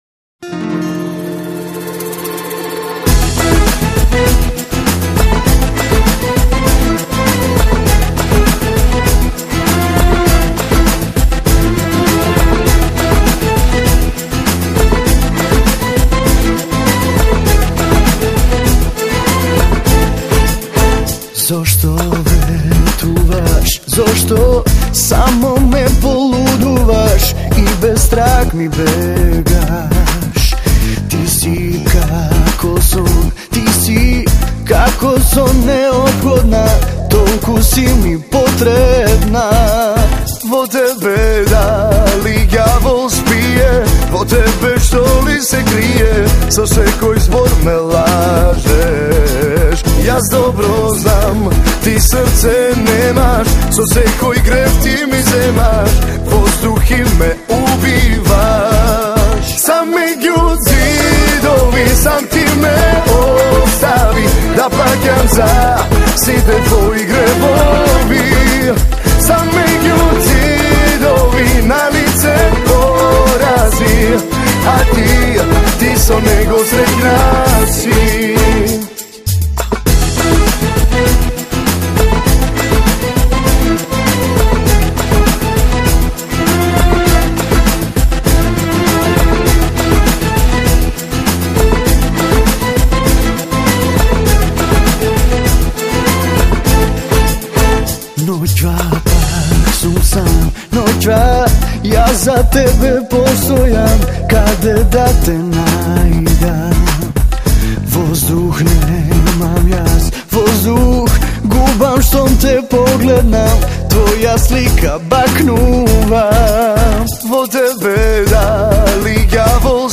风格：流行摇滚